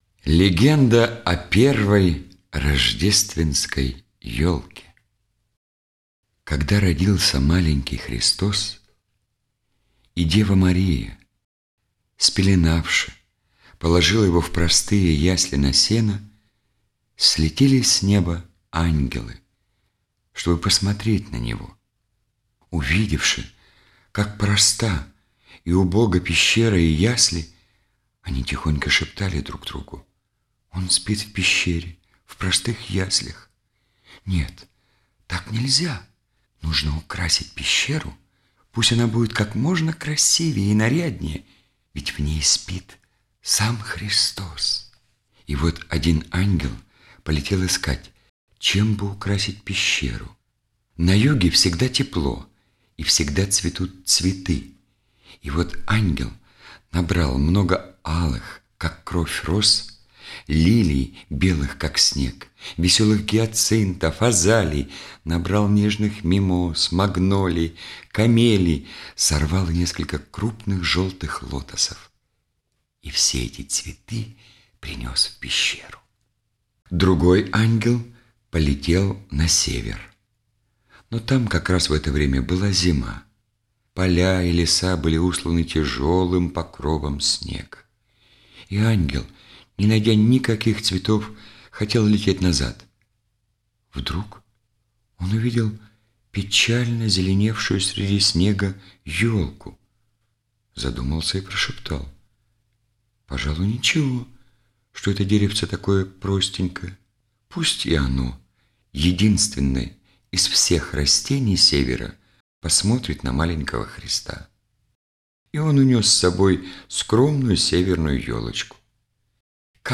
Легенда о первой Рождественской елке - аудио рассказ - слушать онлайн